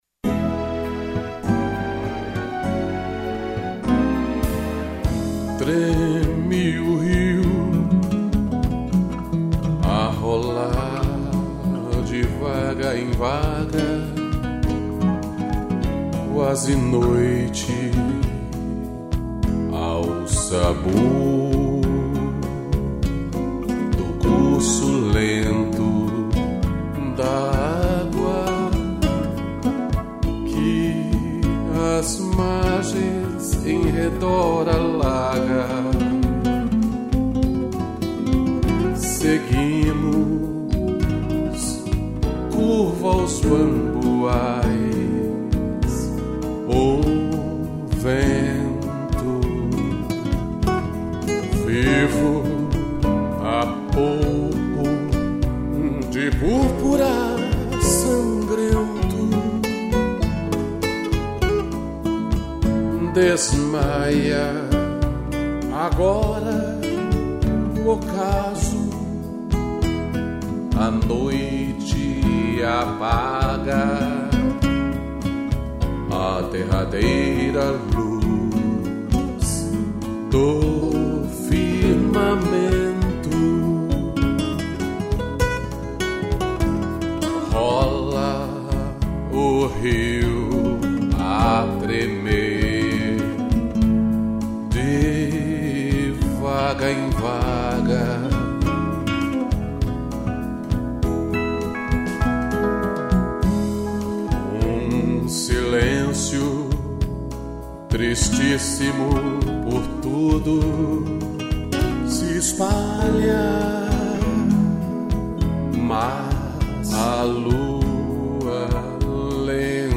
interpretação e violão